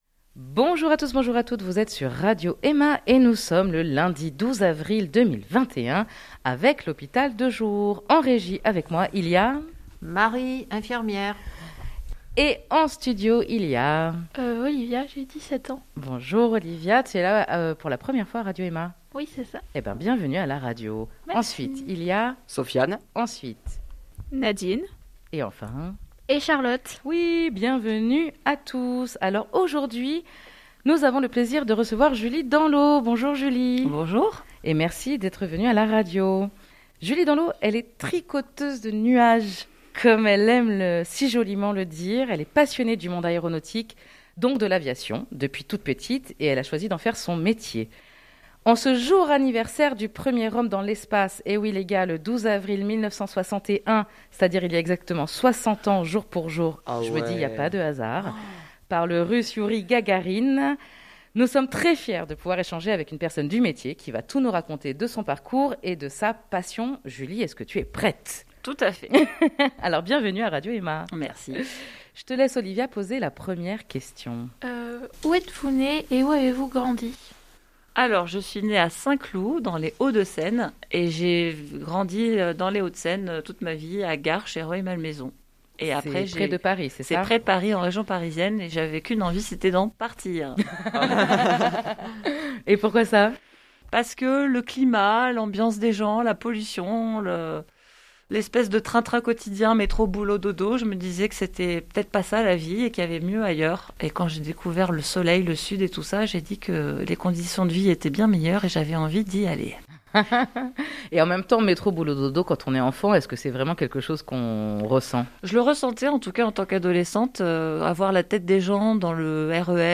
Une émission très dynamique, acrrochez vos ceintures et...